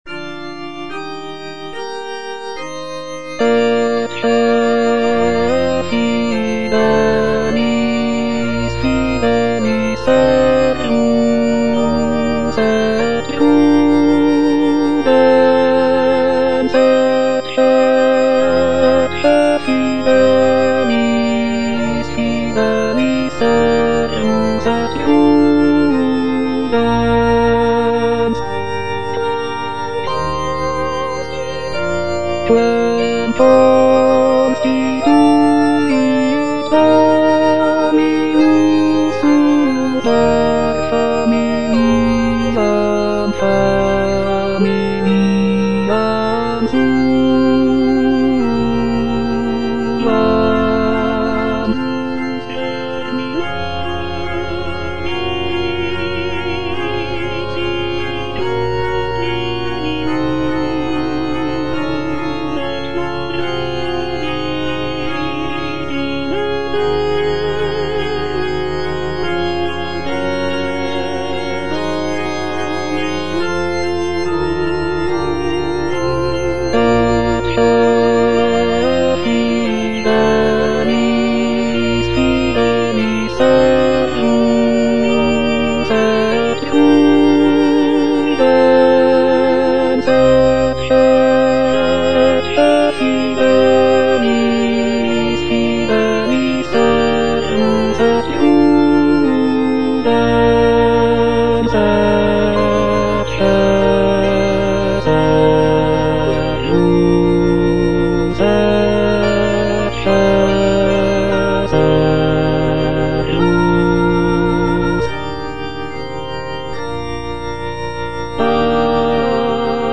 G. FAURÉ - ECCE FIDELIS SERVUS Baritone (Emphasised voice and other voices) Ads stop: